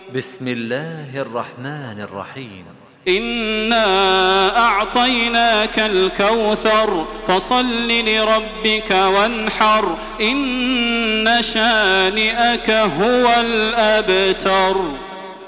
Surah Al-Kawthar MP3 in the Voice of Makkah Taraweeh 1427 in Hafs Narration
Murattal Hafs An Asim